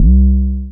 TM88 AmbientBig808.wav